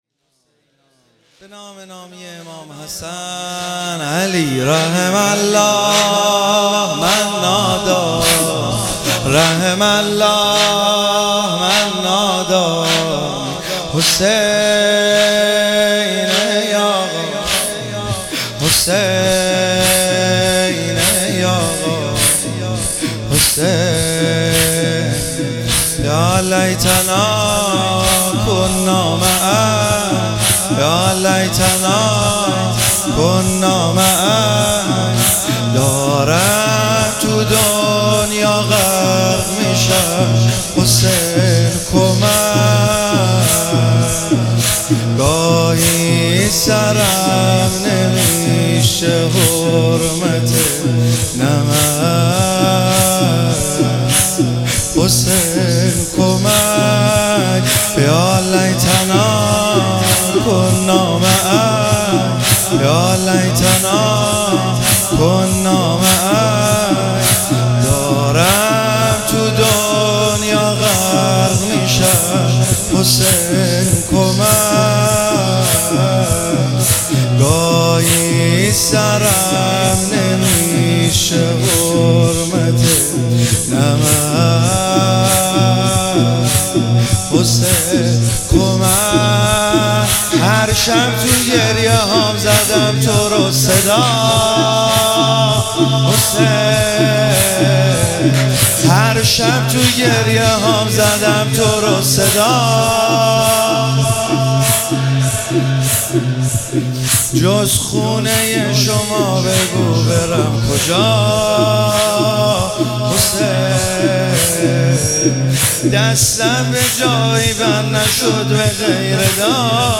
شب هفتم محرم ۱۴۰۱